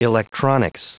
Prononciation du mot electronics en anglais (fichier audio)
Prononciation du mot : electronics